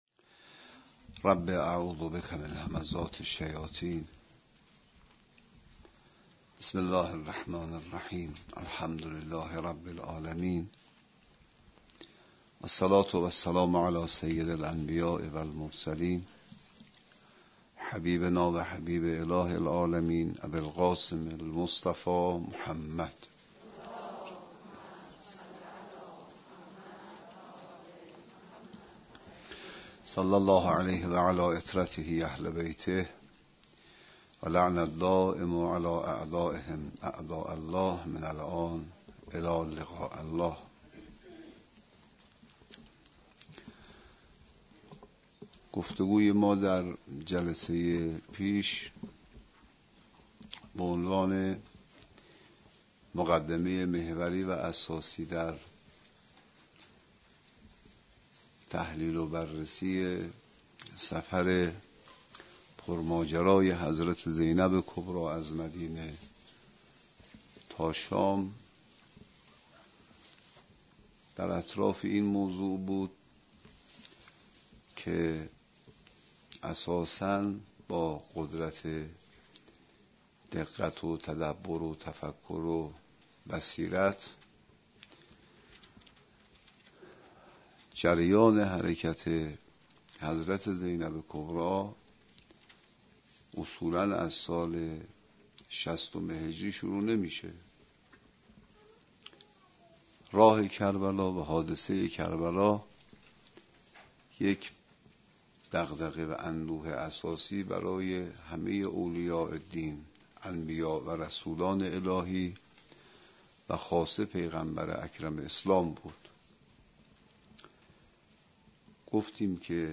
درس 55 و 56 (2 قسمت اول سوره ی نازعات )